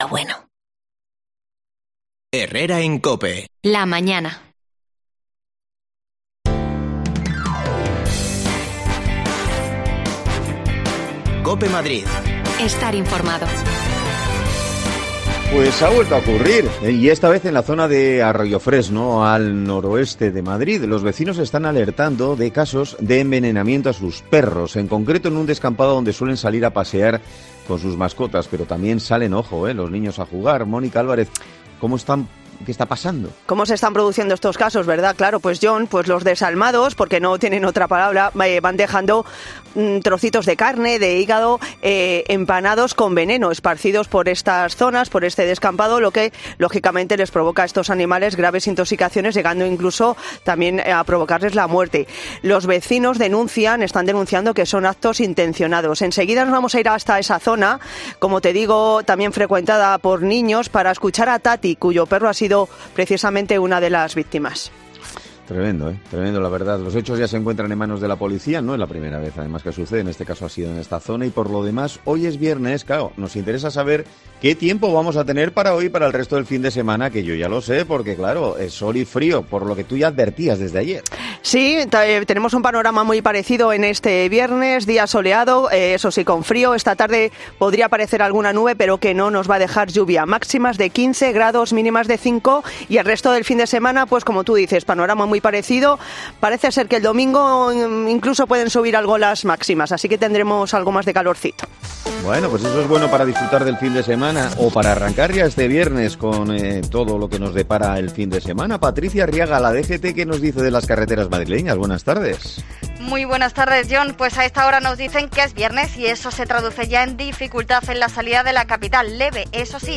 Nos acercamos hasta alli para escuchar a los afectados
Las desconexiones locales de Madrid son espacios de 10 minutos de duración que se emiten en COPE , de lunes a viernes.